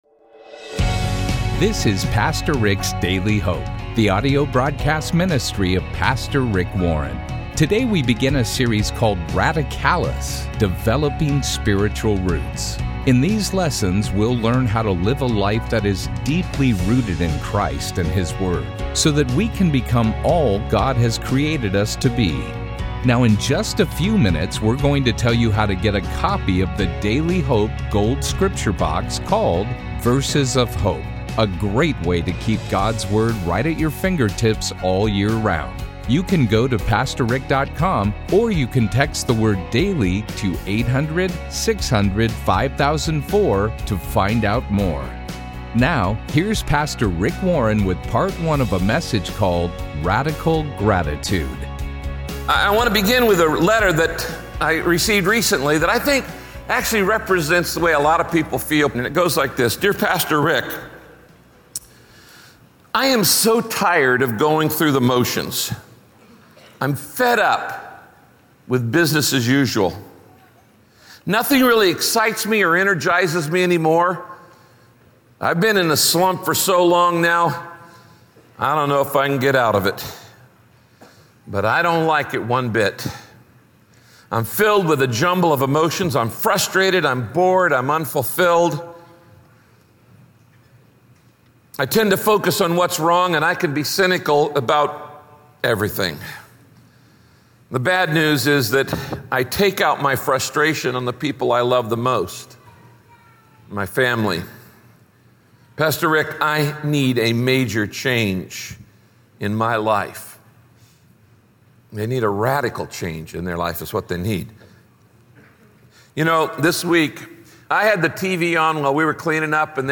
Join Pastor Rick as he teaches from God’s Word.
Radio Broadcast Radical Gratitude – Part 1 The Bible says you’re blessed when you trust God and place your hope and confidence in him.